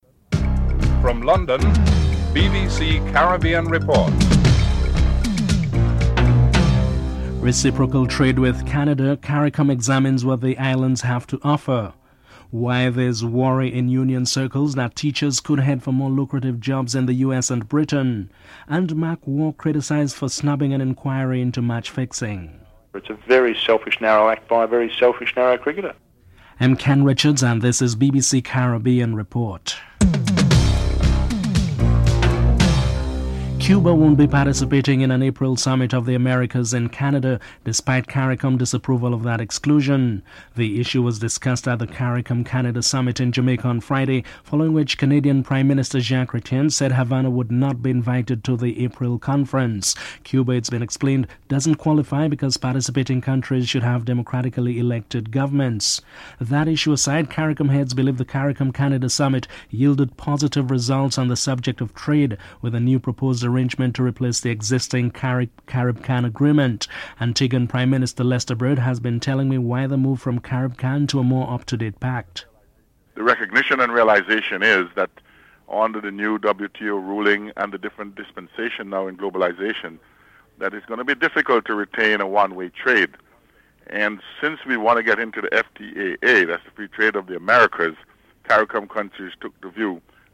1. Headlines (00:00-00:32)
2. Reciprocal trade with Canada, Caricom examines what the islands have to offer. Prime Minister Lester Bird is interviewed (00:33-04:00)